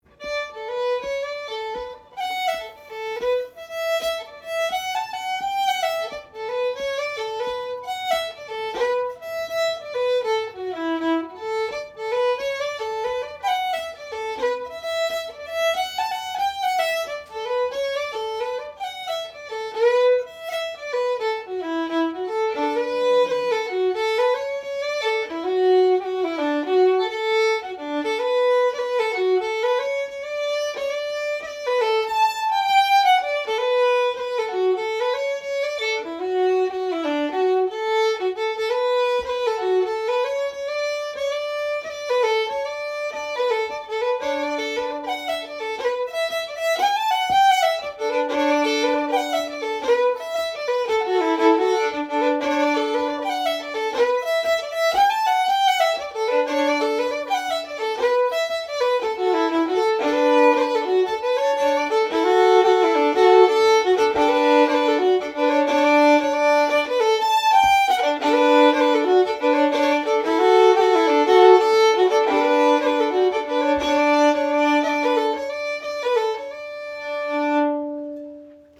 Sessions are open to all instruments and levels, but generally focus on the melody.
Composer Colin Farrell Type Jig Key D Recordings